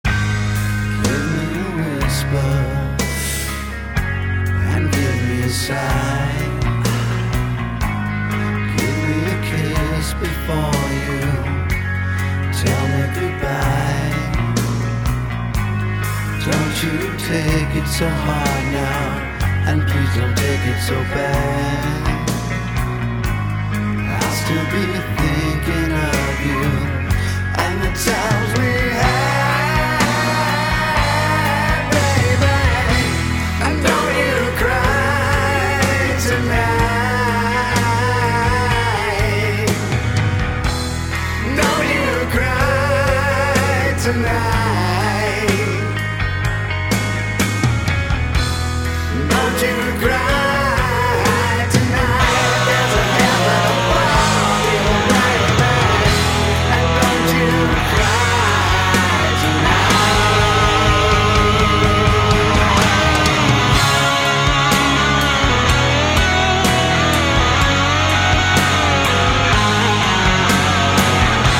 рок группа